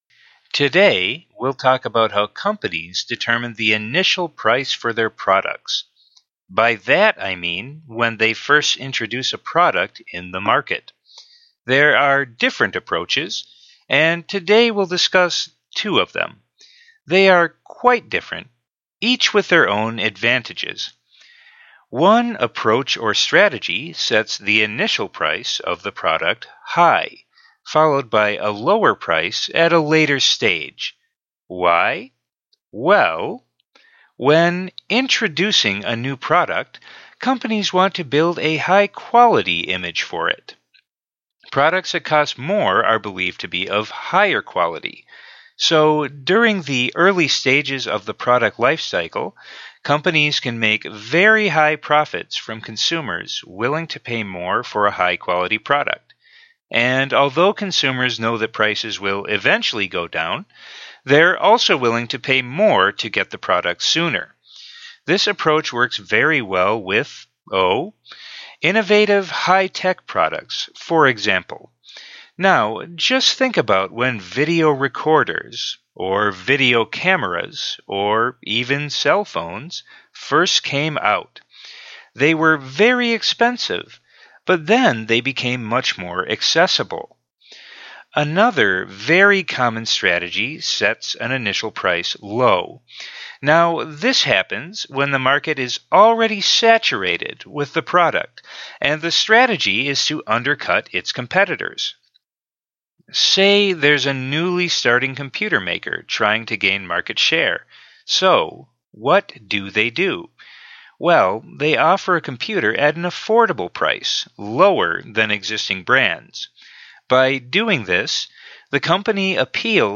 The low intermediate track is slow, but not completely unnatural. The pace of speech is similar to that of an English speaking news announcer.
Track 2: TOEFL Speaking Task 4 Lecture, Low Intermediate Level
QuickPrepV1SpeakingTask4LectureLowInterrmediate.mp3